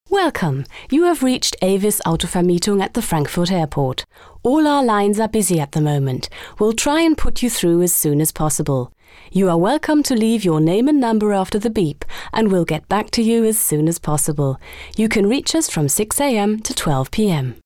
Sprecherin / Schauspielerin in Englisch und Deutsch
Kein Dialekt
Sprechprobe: Werbung (Muttersprache):